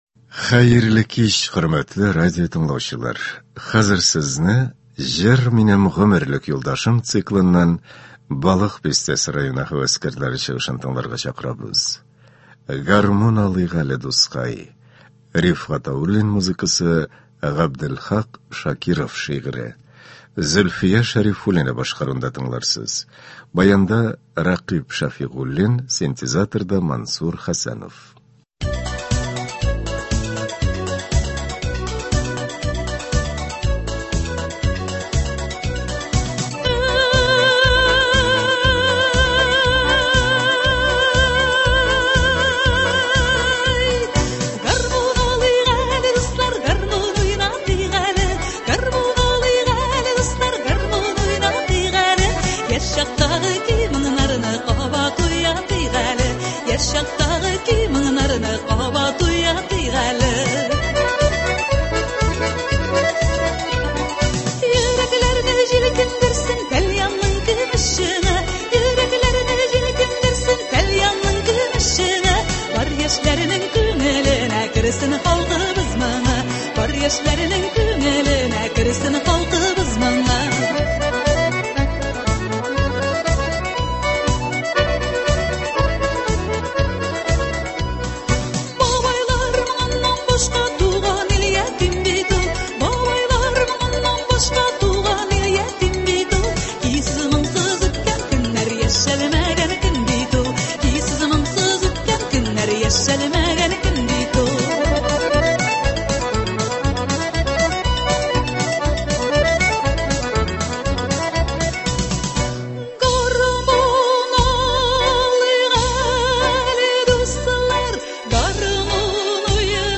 Концерт (04.12.23)